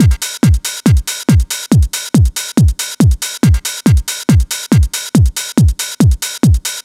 NRG 4 On The Floor 042.wav